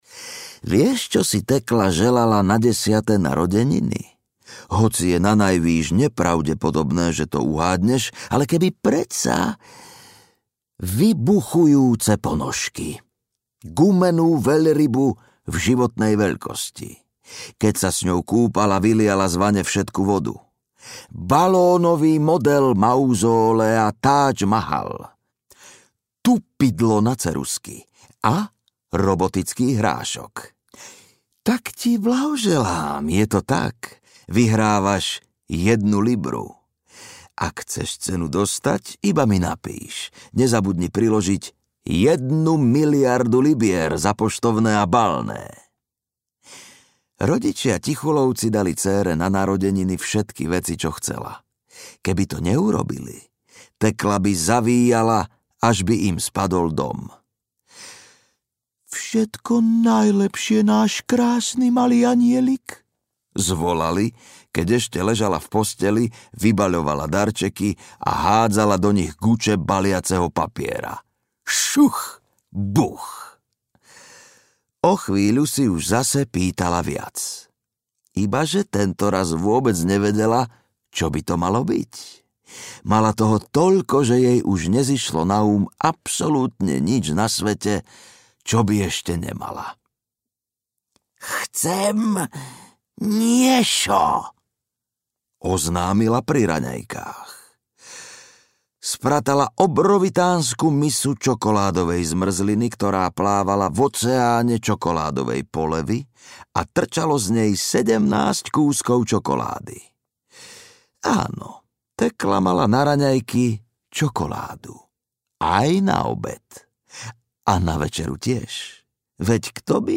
Niešo audiokniha
Ukázka z knihy